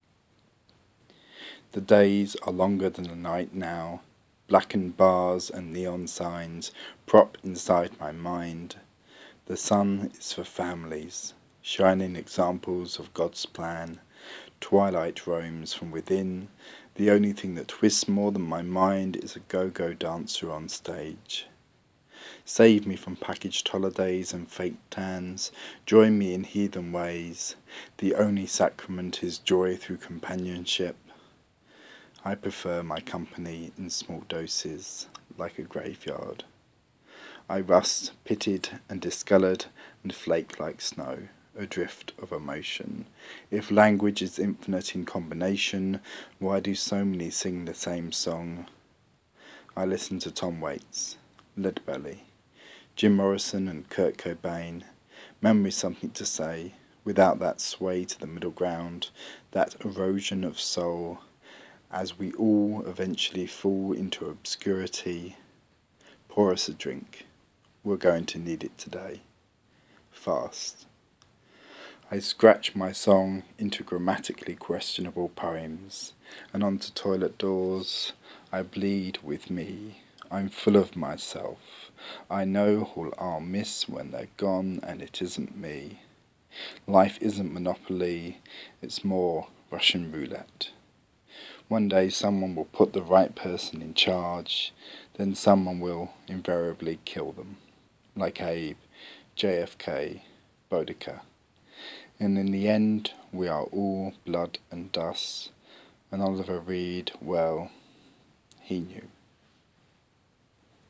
Morbid Spoken Word